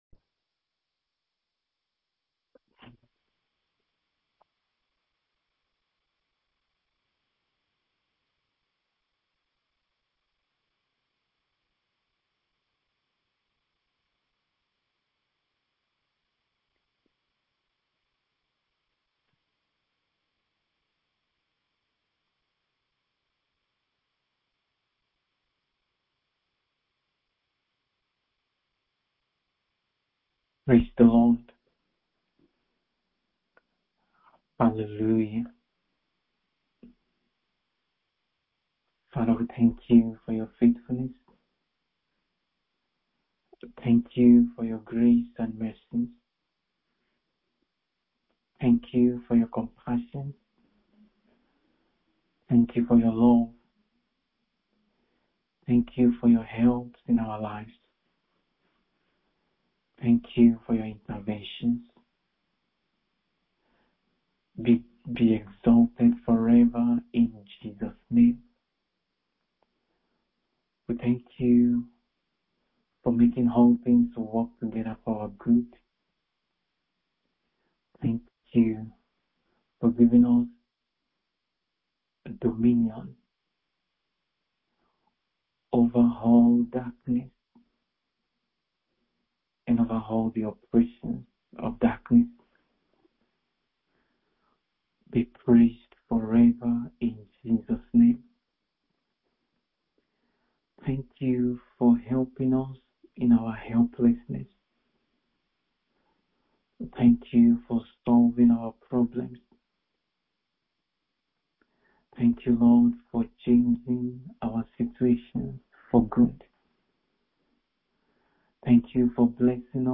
MIDNIGHT PRAYER SESSION : 08 MARCH 2025